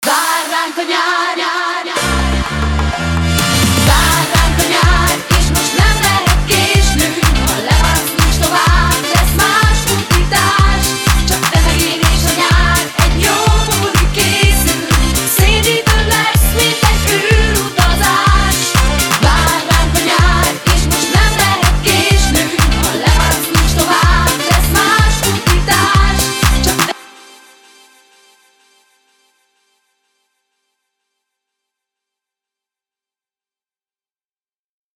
Disco/Funky extended és radio edit